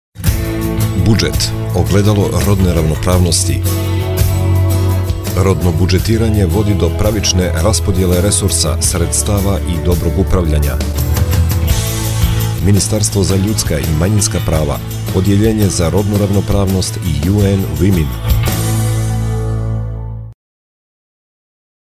Rodno budzetiranje-radio dzingl 2